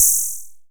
MKS80 FX1.wav